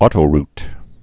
tō-rt)